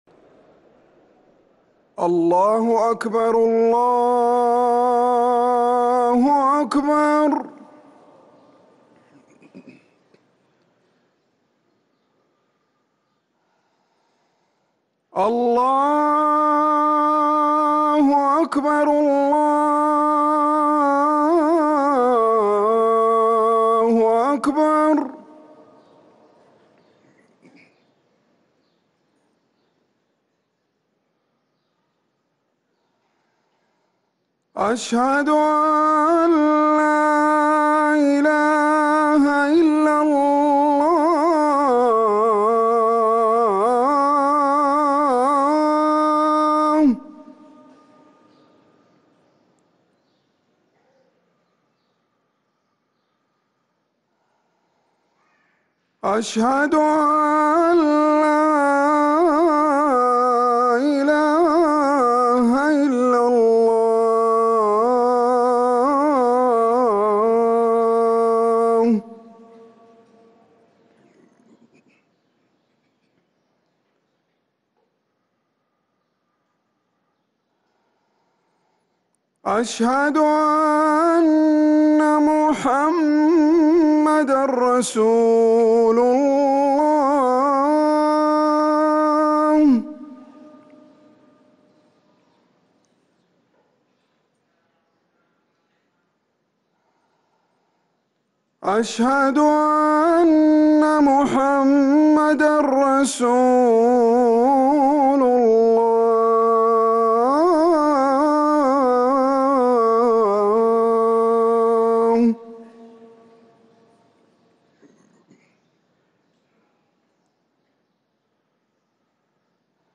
اذان الظهر
ركن الأذان